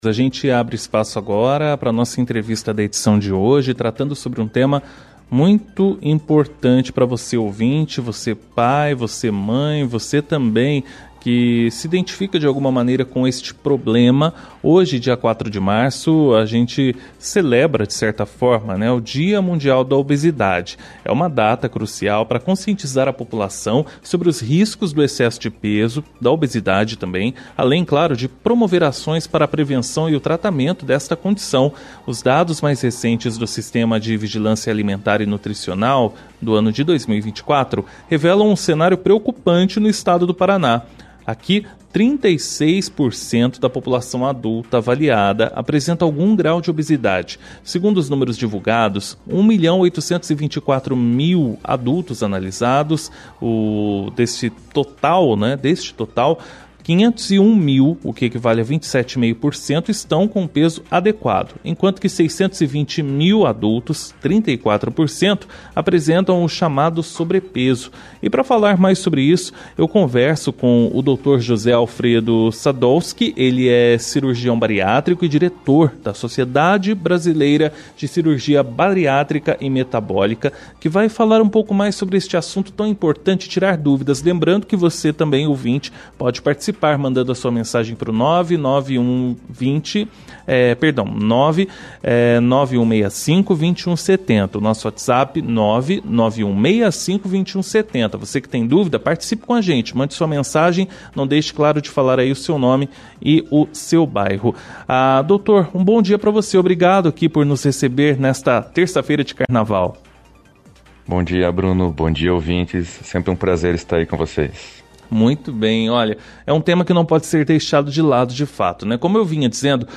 ENTREVISTA-OBESIDADE-0403.mp3